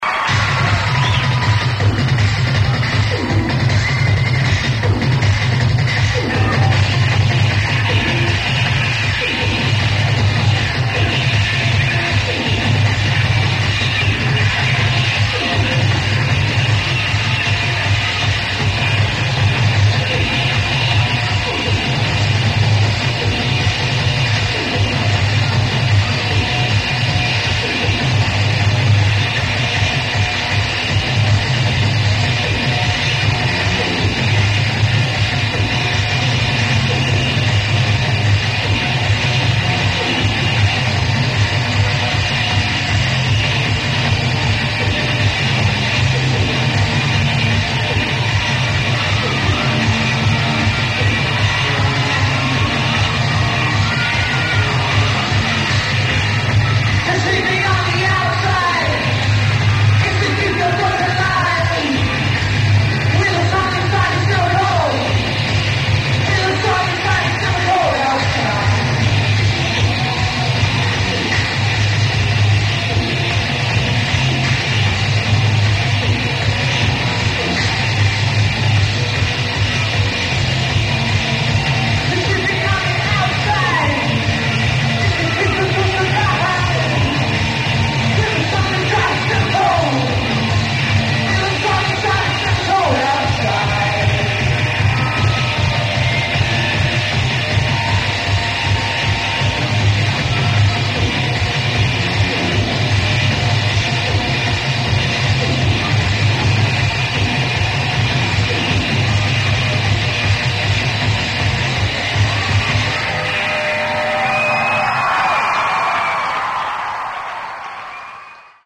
Paramount Theater